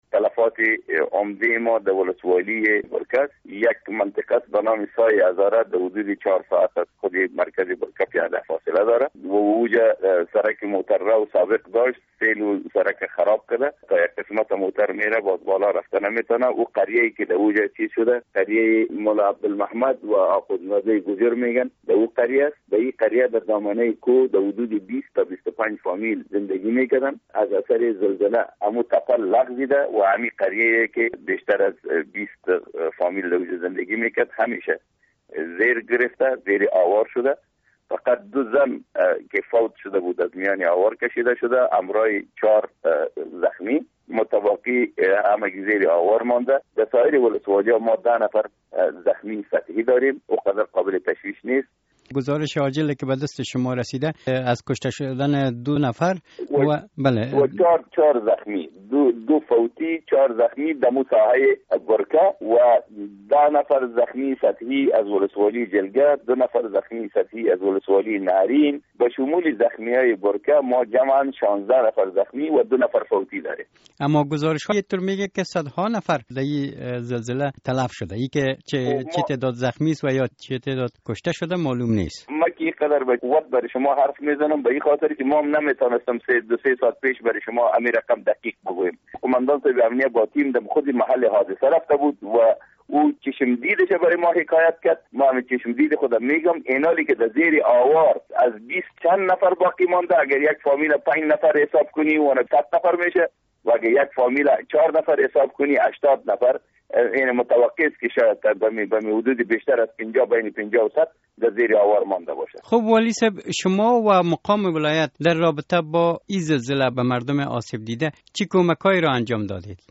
با والی بغلان در مورد تلفات و خساراتی ناشی از زلزله